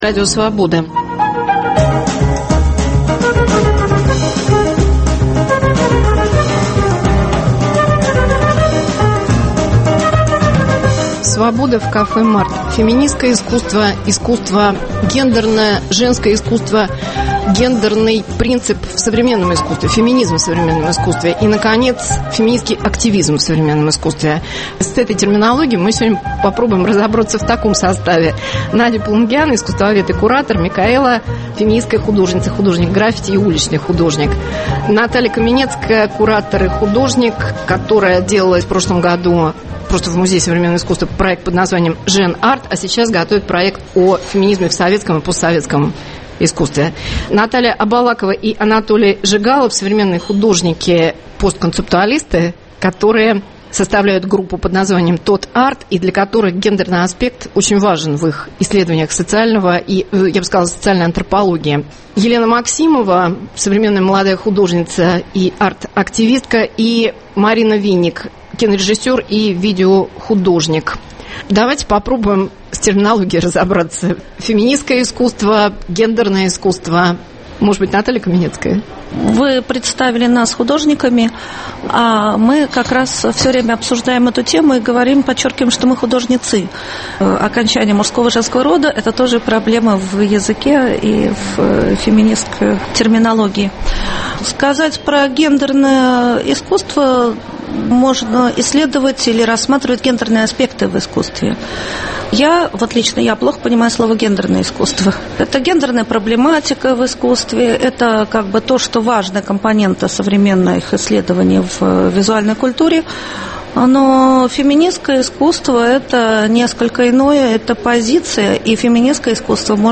Свобода в кафе МАРТ.